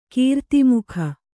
♪ kīrtimukha